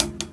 double_hattingz.wav